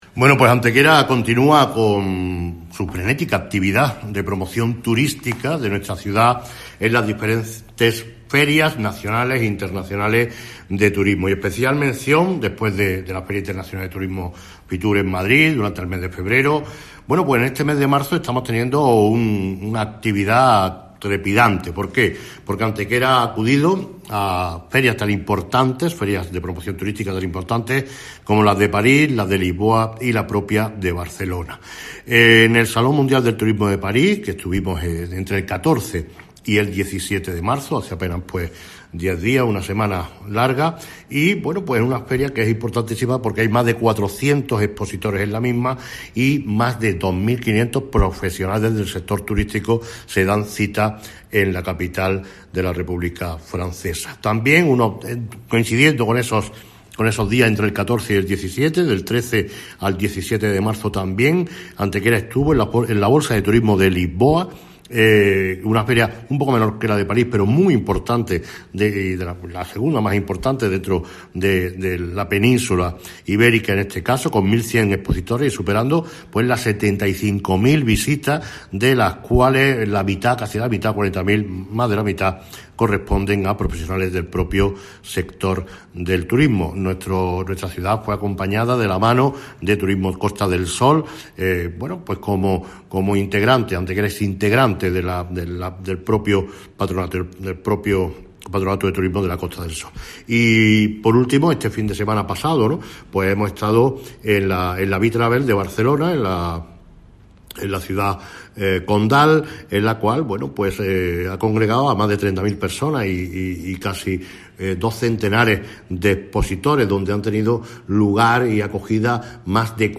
El alcalde de Antequera, Manolo Barón, informa de las últimas y más destacadas presencias promocionales de nuestra ciudad como destino turístico de excepción durante el presente mes de marzo. Tres han sido las citas principales en las que Antequera se ha promocionado turísticamente en enclaves que son muy importantes para el turismo local como son París, Lisboa y Barcelona.
Cortes de voz M. Barón 1205.04 kb Formato: mp3